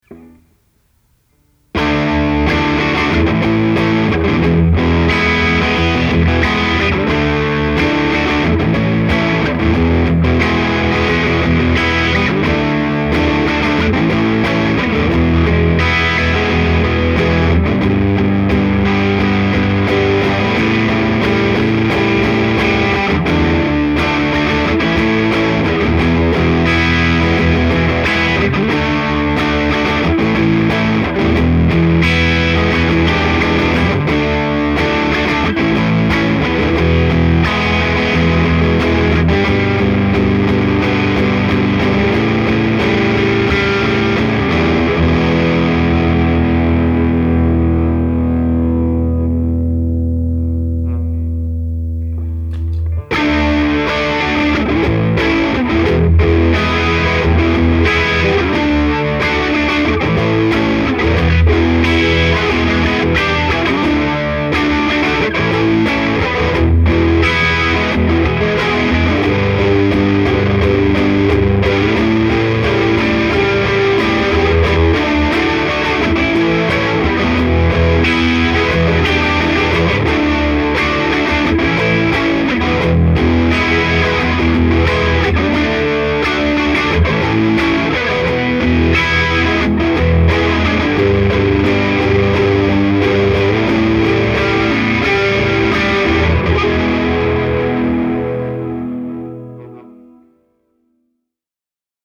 Swirling/Dirty
In this final clip, I add a little more depth and FX Level, then get into the dirty channel of my Hot Rod Deluxe. The result is a flange-like swirling tone.
corona_drive.mp3